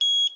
Positive Beep.wav